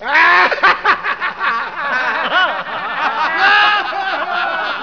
tolpa.wav